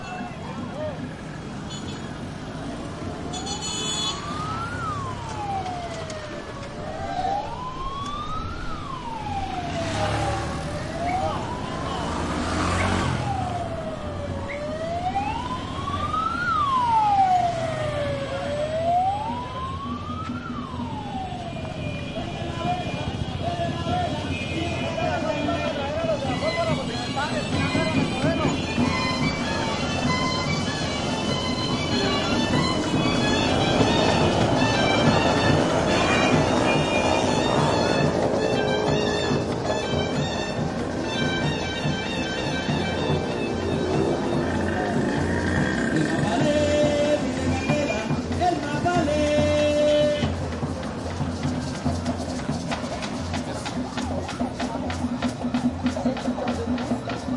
描述：记录在哥伦比亚圣玛尔塔的一条街道上
Tag: 玛塔 哥伦比亚 交通 人民 圣诞老人 噪音